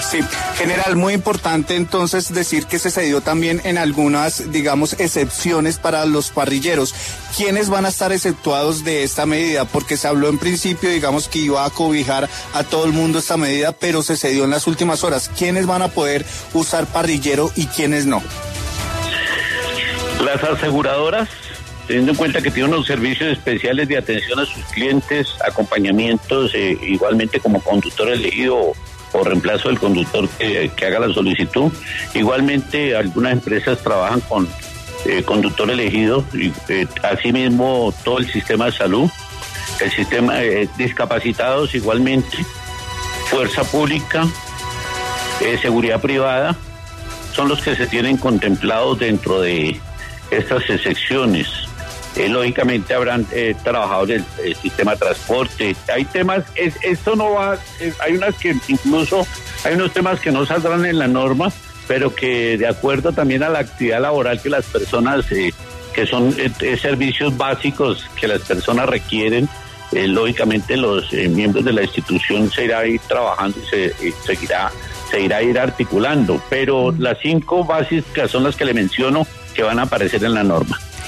Por ello, en diálogo con W Radio, el general Eliecer Camacho, comandante de la Policía Metropolitana de Bogotá, explicó las razones por las cuales el distrito no reversó la decisión de restringir el parrillero en moto los jueves, viernes y sábados de 7 pm a 4 am.